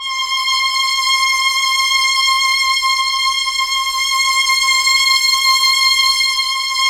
Index of /90_sSampleCDs/Keyboards of The 60's and 70's - CD1/KEY_Chamberlin/STR_Cham Slo Str
STR_Chb StrC_7-L.wav